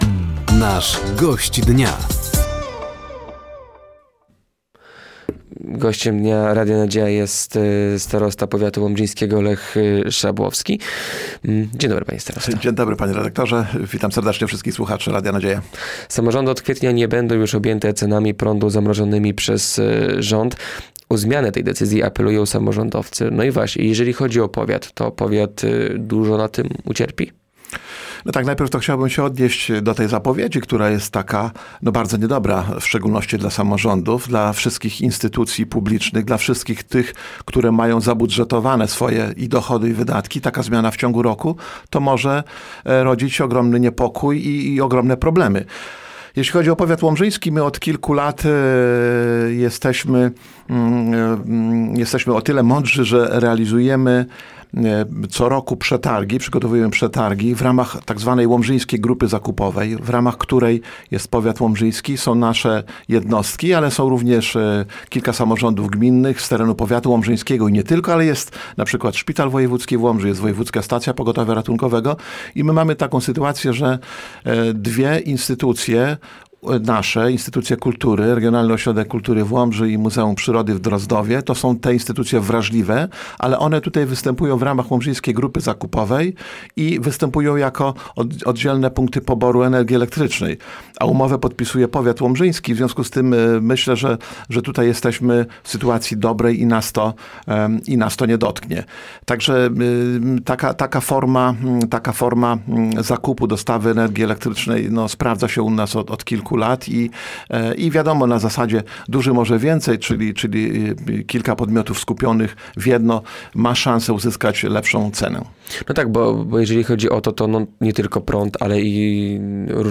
Gościem Dnia Radia Nadzieja był starosta łomżyński Lech Szabłowski. Tematem rozmowy była między innymi decyzja rządu dotycząca wykluczenia samorządów z zamrożenia cen prądu, centra integracji cudzoziemców i sytuacja finansowa powiatu.